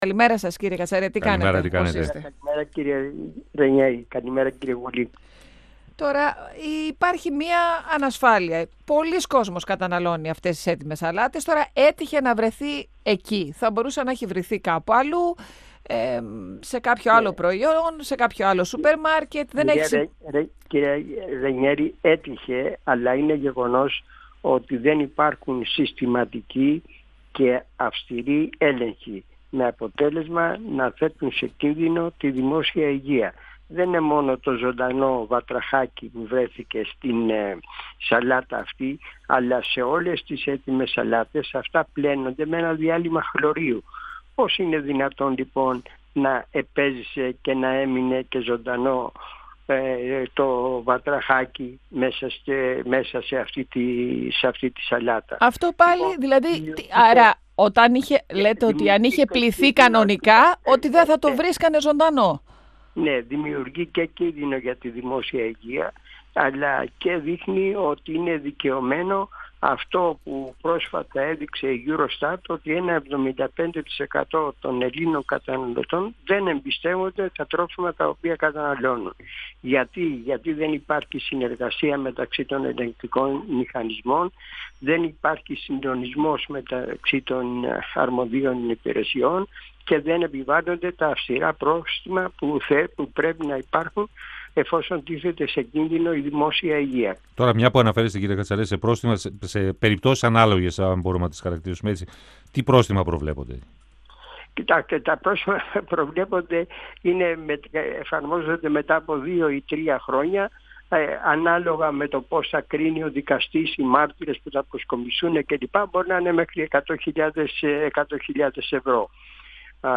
O πρώην πρόεδρος του ΕΦΕΤ , Νίκος Κατσαρός στον 102FM του Ρ.Σ.Μ. της ΕΡΤ3